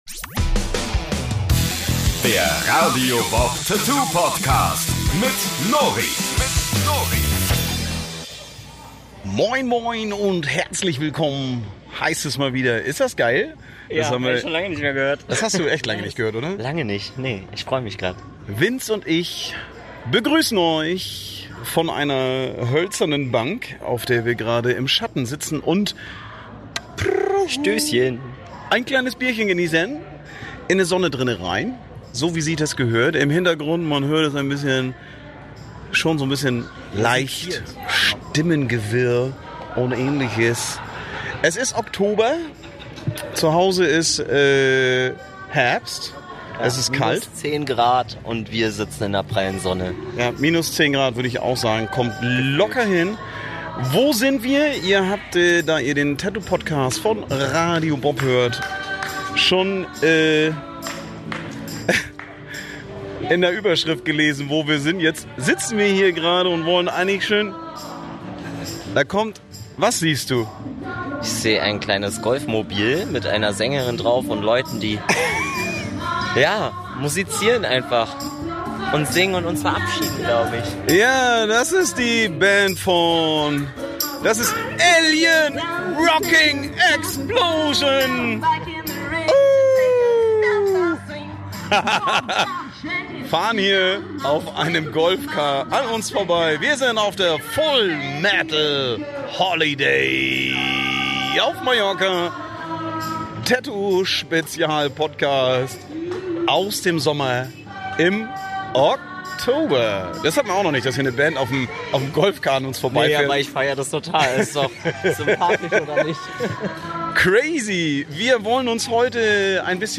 Unter der balearischen Sonne wird mit Gästen geschnackt, die gerade noch im Tattoo-Studio sitzen, mit Gästen, die es gerade hinter sich haben und mit stolzen Erst-Tätowierten!